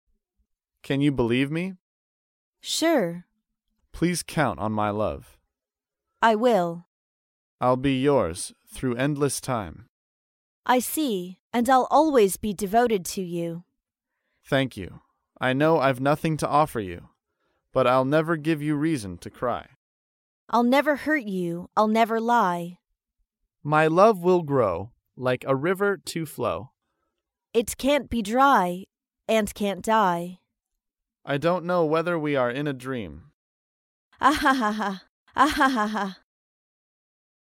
在线英语听力室高频英语口语对话 第168期:山盟海誓的听力文件下载,《高频英语口语对话》栏目包含了日常生活中经常使用的英语情景对话，是学习英语口语，能够帮助英语爱好者在听英语对话的过程中，积累英语口语习语知识，提高英语听说水平，并通过栏目中的中英文字幕和音频MP3文件，提高英语语感。